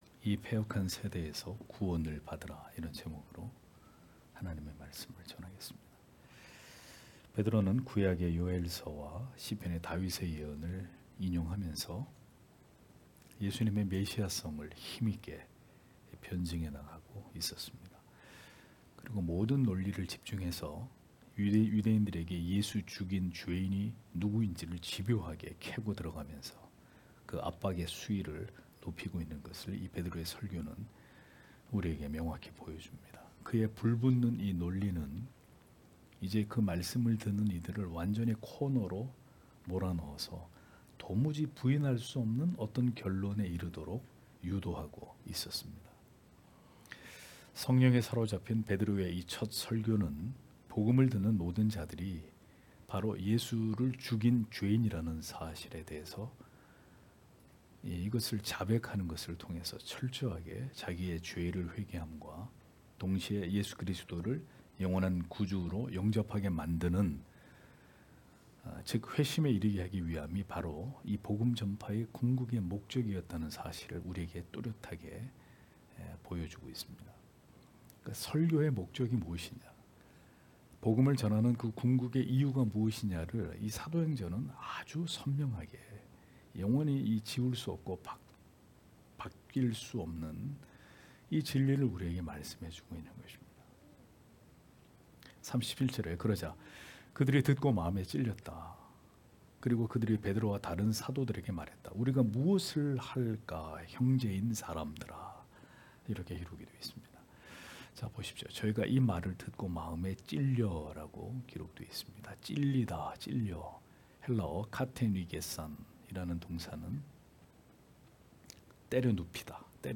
금요기도회 - [사도행전 강해 17] 이 패역한 세대에서 구원을 받으라 (행 2장 37- 41절)